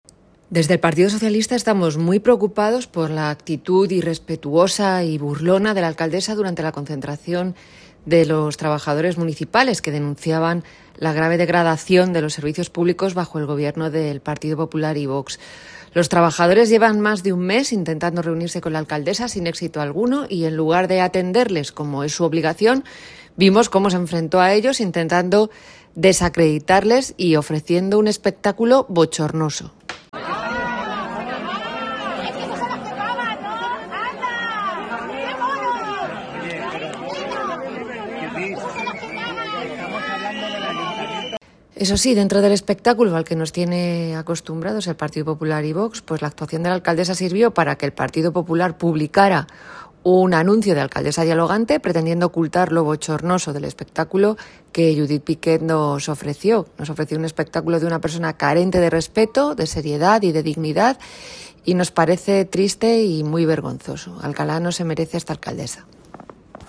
En la jornada de ayer, jueves 13 de marzo, los representantes de los trabajadores municipales llevaron a cabo una concentración en la puerta del Ayuntamiento de Alcalá de Henares motivada por la degradación que están sufriendo los servicios públicos por parte del gobierno PP y VOX debido a la falta de personal.
Enfatizar expresiones con “¡COÑO!”, reírse de forma estridente y exagerada, soltar soflamas partidistas y tener una actitud chulesca están en las antípodas de lo que los vecinos y vecinas de Alcalá quieren para su máximo representante.